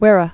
(wĭrə)